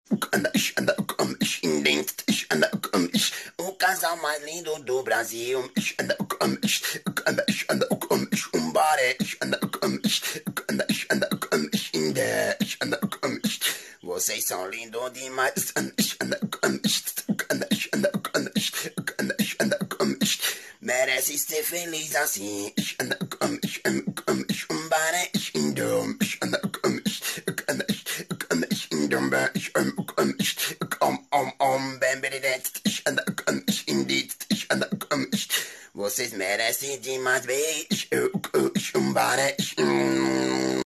Meme Sound Effects